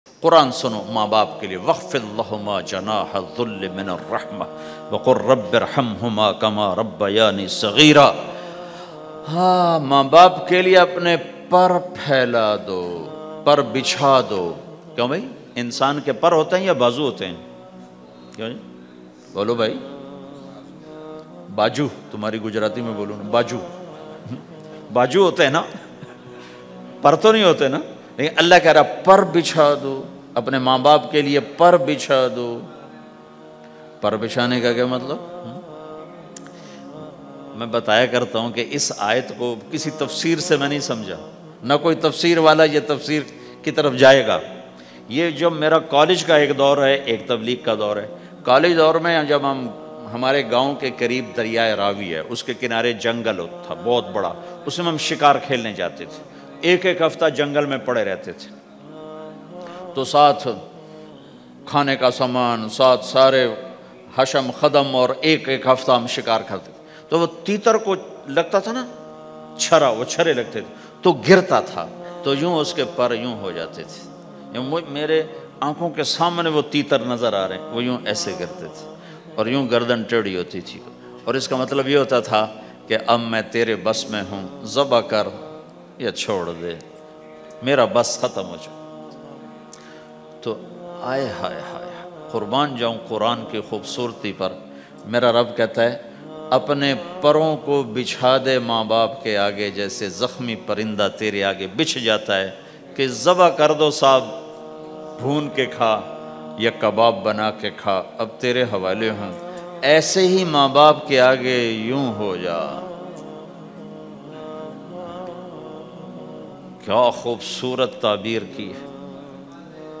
Mere Nabi Ka Zakham, listen or play online mp3 bayan in the voice of Maulana Tariq Jameel.